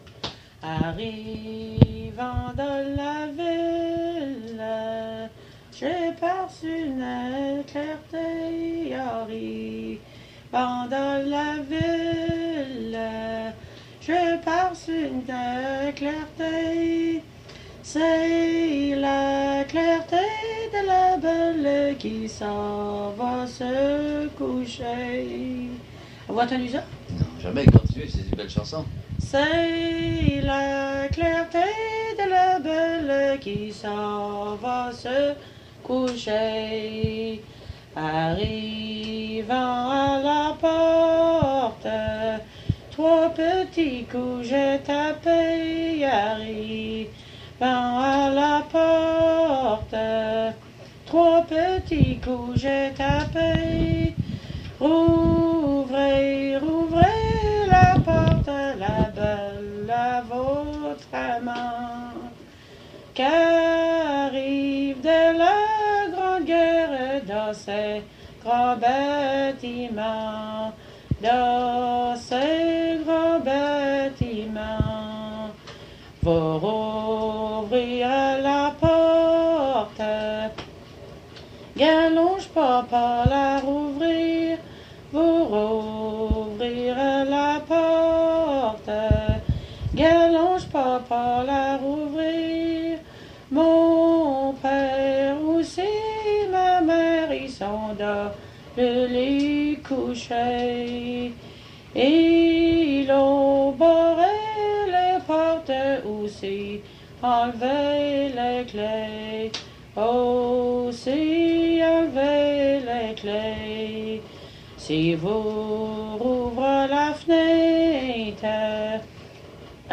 Emplacement Cap St-Georges